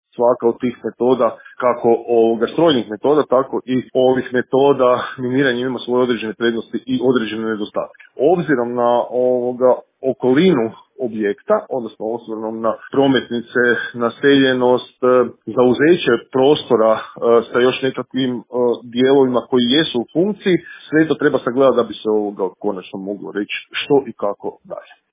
O metodama i načinu rušenja razgovarali smo u Intervjuu Media servisa